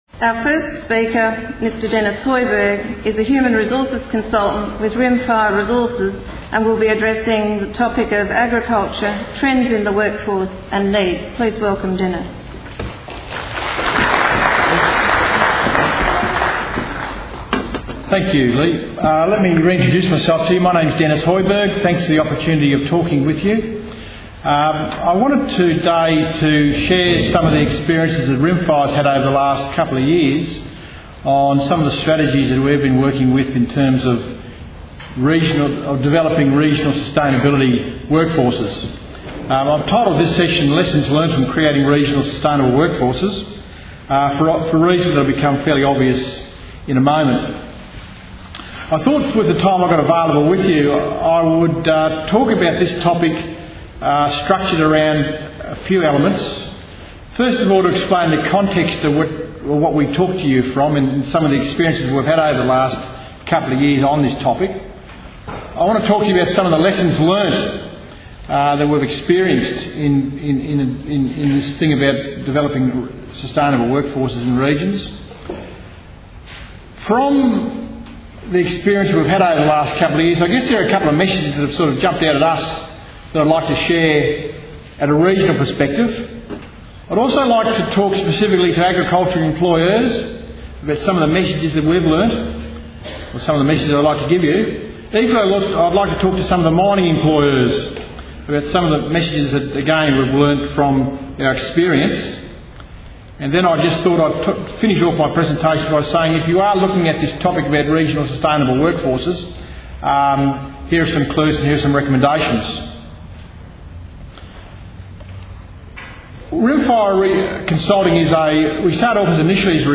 Agriculture: trends in the workforce and needs.- Sustaining Rural Communities Conference 2010 Presentation | Inside Cotton